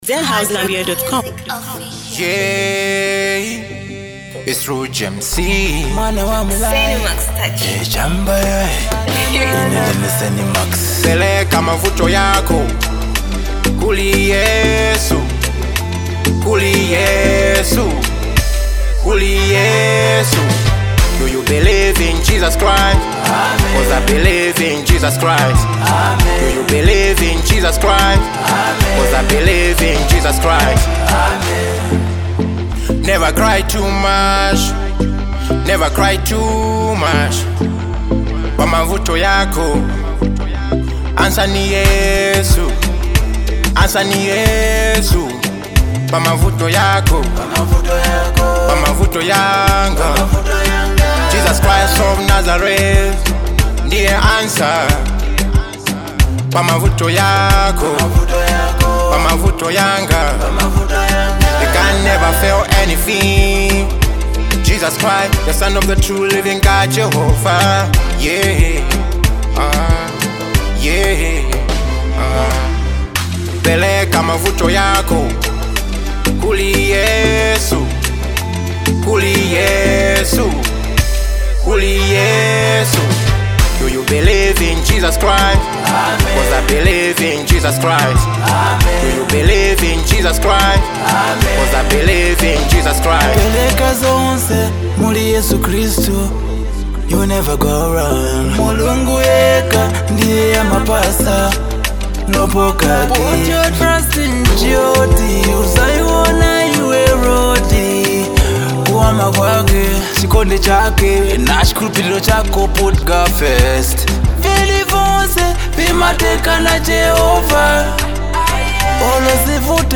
A powerful gospel anthem filled with faith and hope
With uplifting vocals and heartfelt lyrics